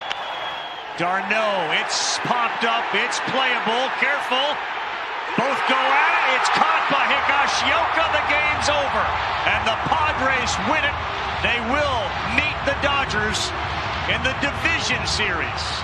MLB-Padres-Braves-Final-Out-Call.mp3